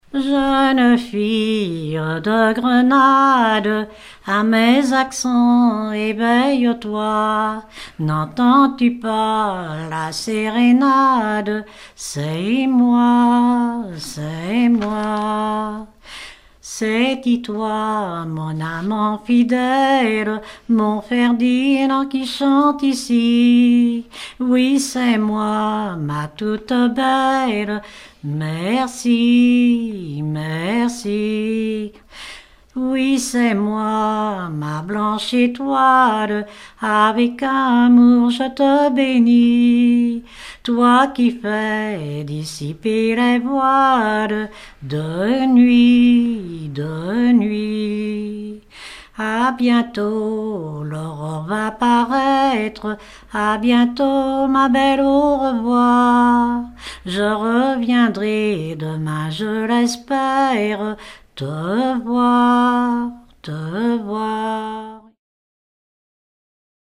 Dompierre-sur-Yon
Chants brefs - De noces
Genre strophique